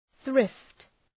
Προφορά
{ɵrıft}